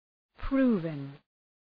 Προφορά
{‘pru:vən}